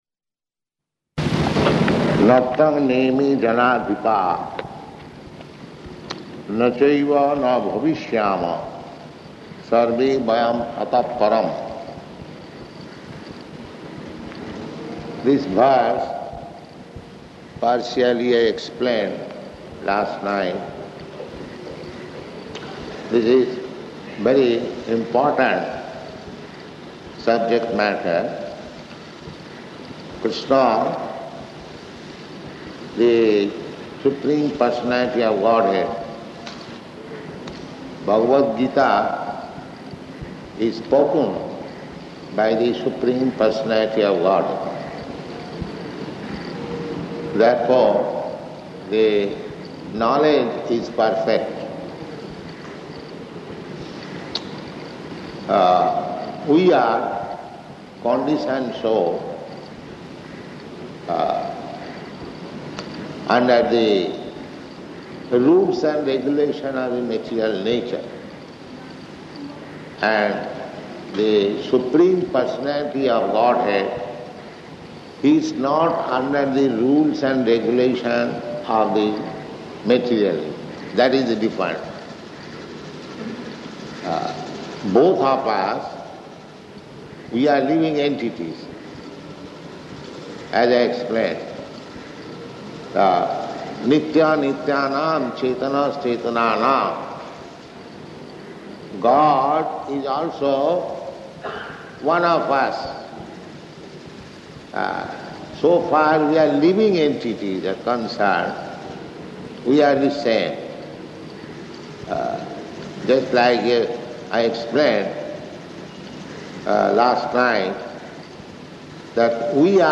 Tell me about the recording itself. Location: Jakarta